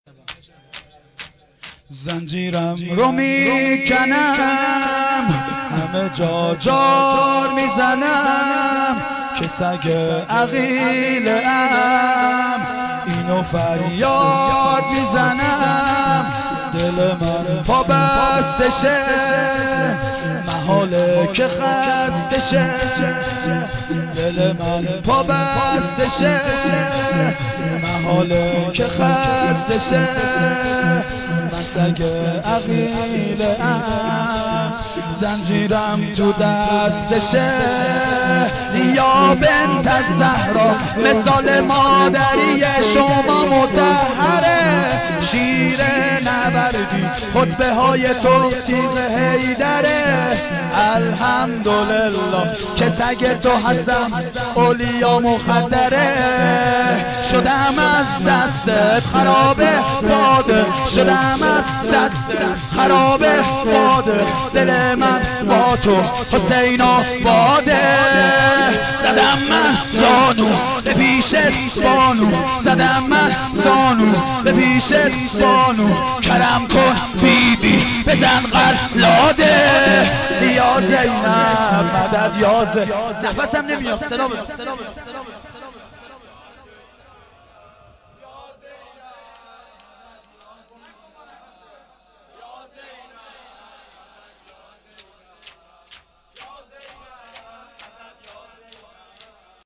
شورجدید
هفتگی هجدهم اردیبهشت